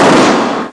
bigblast.mp3